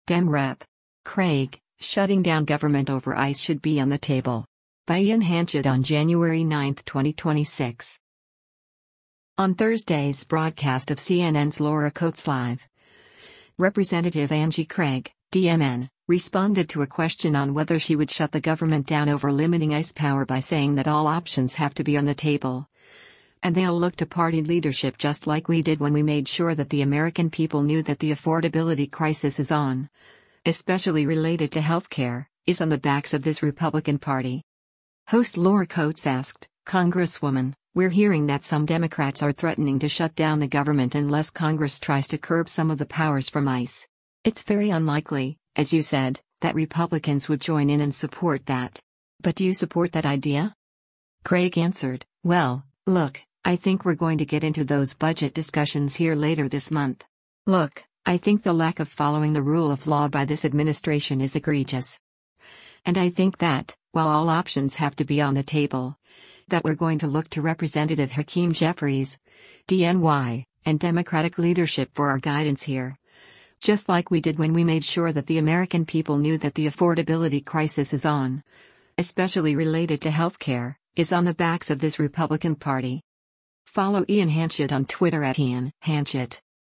On Thursday’s broadcast of CNN’s “Laura Coates Live,” Rep. Angie Craig (D-MN) responded to a question on whether she would shut the government down over limiting ICE power by saying that “all options have to be on the table,” and they’ll look to party leadership “just like we did when we made sure that the American people knew that the affordability crisis is on — especially related to healthcare — is on the backs of this Republican Party.”